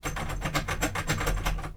sfx_action_doorknob_03.wav